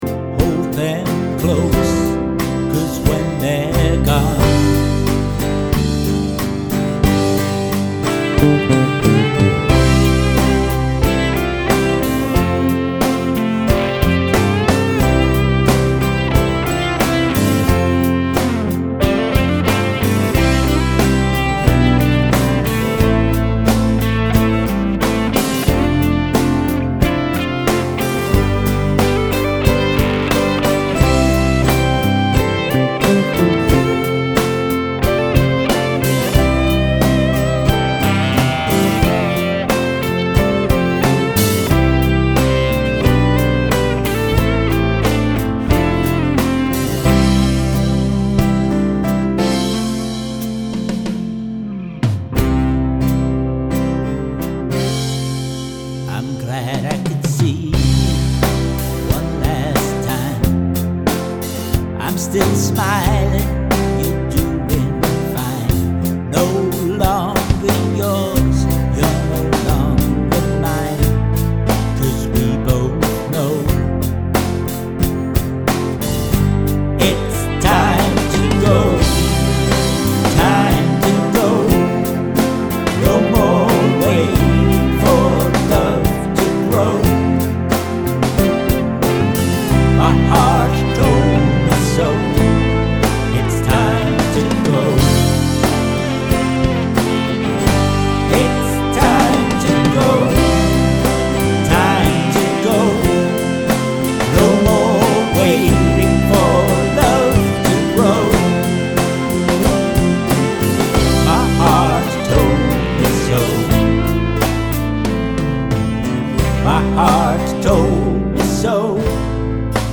The Cellmates are based in Surrey and play a diverse set of unconventional covers.
The Cellmates are a group of four Surrey based musicians who play sets consisting of unconventional takes on covers plus a good mixture of our own material. Here is a link to an mp3 of a recent rehearsal track followed by links to our Youtube space with some recent extracts from some live gigs.